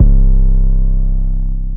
Spinz 808 (Distortion).wav